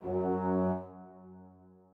strings2_3.ogg